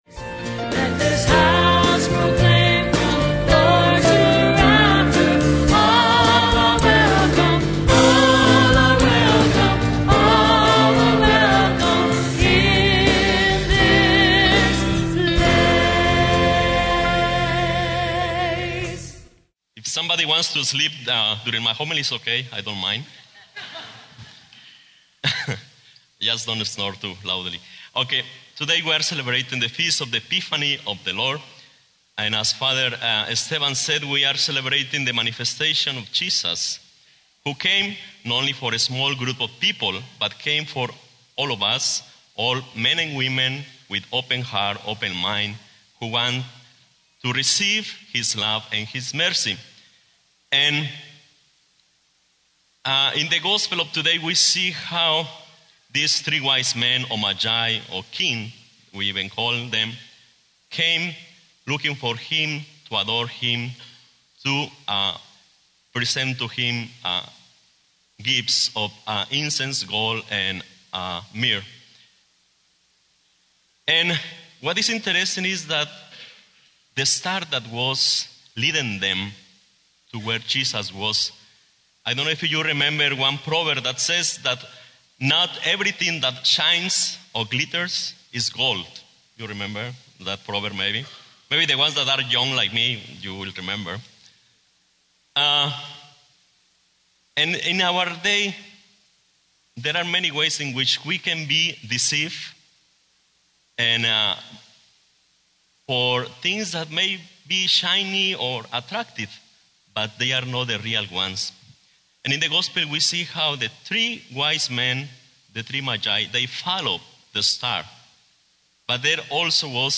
Homily - 1/2/11 - The Epiphany of the Lord - St Monica Catholic Community Media Center